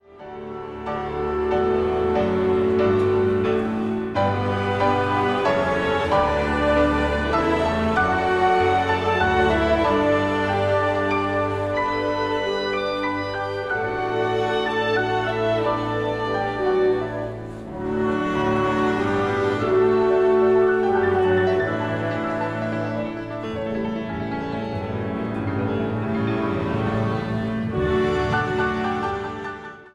I. gemächlich
II. langsam